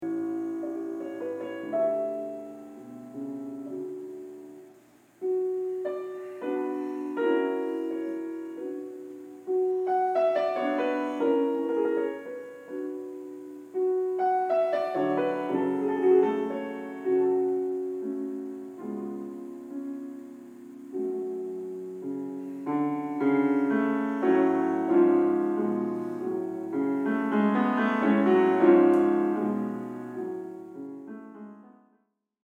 A certain contrast, which sounds more down-to-earth (or should I say BACK-to-earth?), a certain suffering involved, a kind of “reality check” follows our “prayer”: